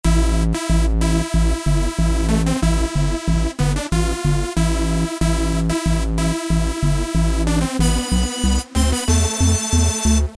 描述：电子风格的低音，用于主合成器。
Tag: 130 bpm Electro Loops Bass Loops 1.24 MB wav Key : Unknown